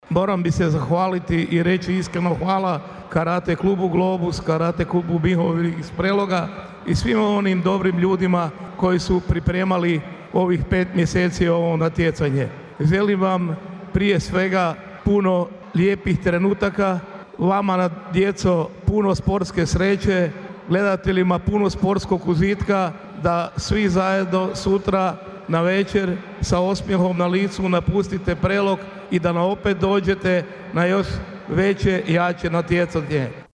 U tijeku je prvi dan natjecanja u školskoj sportskoj dvorani koje je prethodno na kratkoj svečanosti otvorenim proglasio gradonačelnik Ljubomir Kolarek: